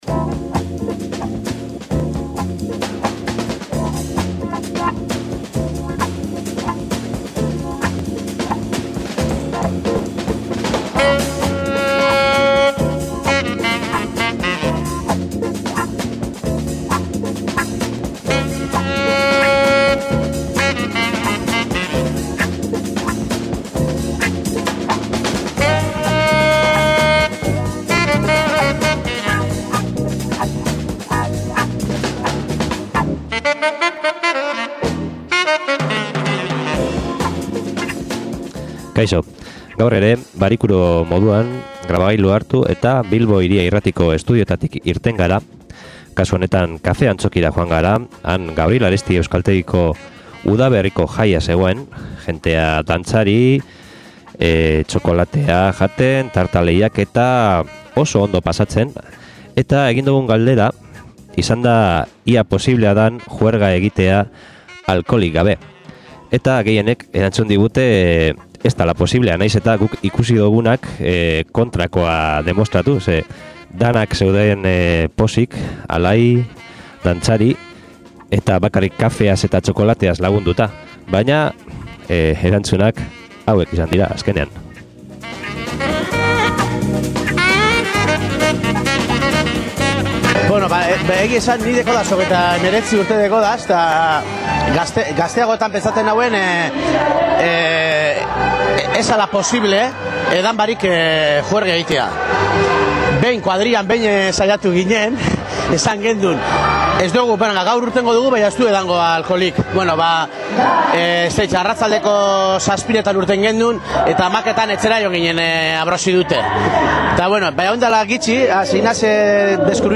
INKESTA
SOLASALDIA